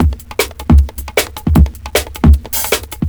JUNGLE1-L.wav